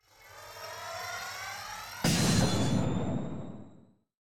Psychic_insanity_lance_charge_fire.ogg